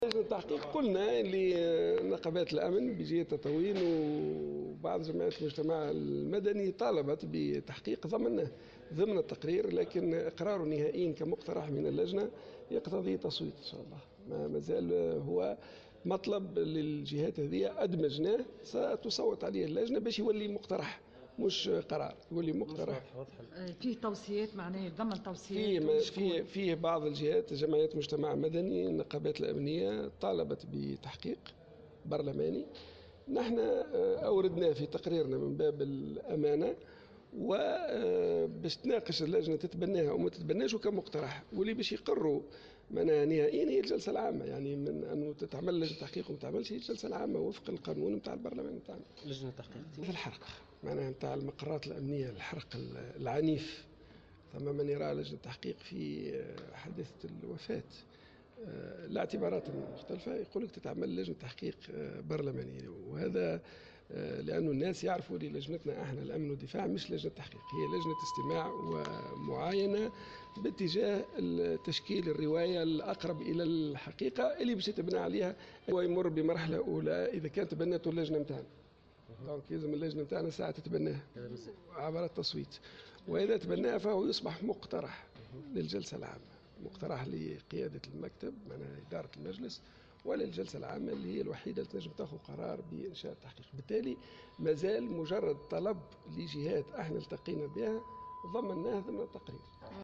أكد عبد اللطيف المكي رئيس لجنة الأمن والدفاع في تصريح لمراسلة الجوهرة "اف ام" أن نقابات الأمن بجهة تطاوين ومكونات المجتمع المدني طالبت بتحقيق في الأحداث التي شهدتها الجهة خلال اعتصام الكامور.